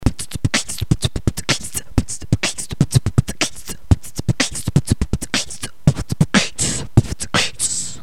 Форум российского битбокс портала » Реорганизация форума - РЕСТАВРАЦИЯ » Выкладываем видео / аудио с битбоксом » !!!NEW!!!
b ts ts b kch ts ts b ts bb ts kch ts ts -
b tsb kchts b ts kch ts